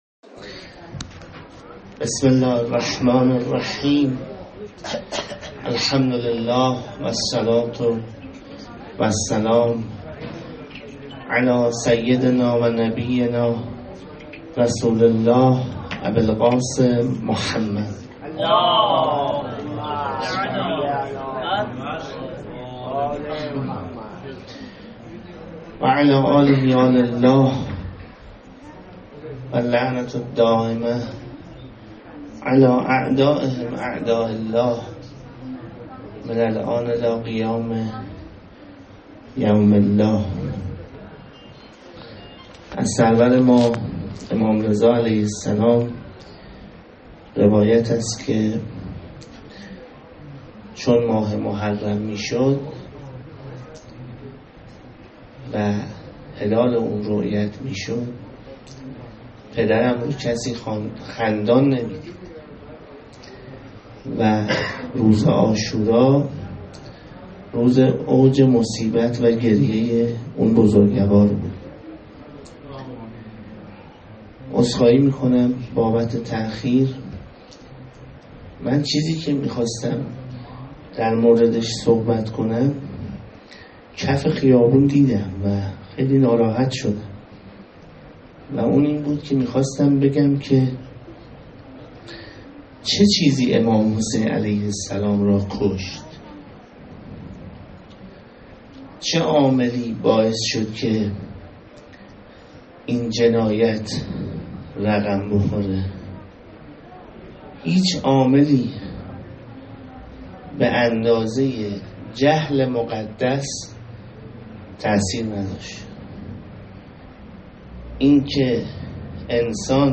به گزارش خبرنگار ایکنا، مراسم سخنرانی و عزاداری شب اول ماه محرم، شامگاه 16 تیرماه در هیئت انصارالزهرا(س) برگزار شد.